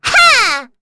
Rehartna-Vox_Attack5.wav